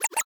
menu-direct-click.ogg